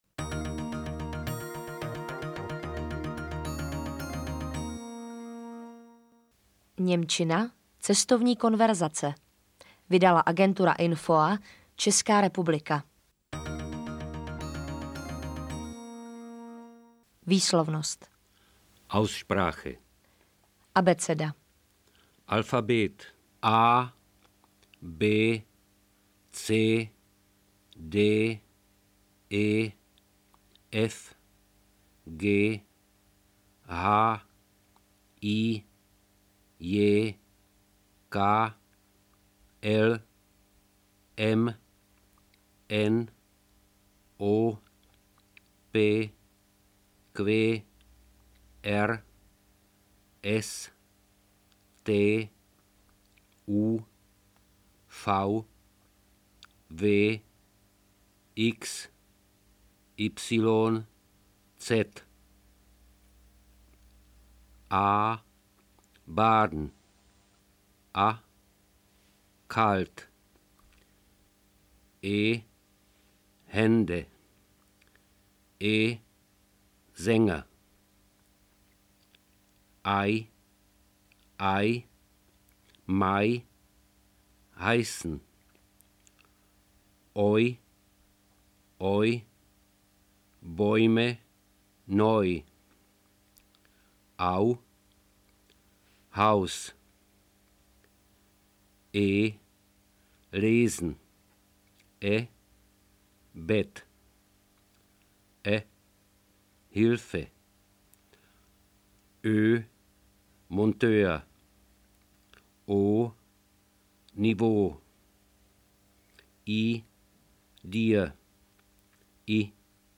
AudioKniha ke stažení, 32 x mp3, délka 46 min., velikost 39,6 MB, česky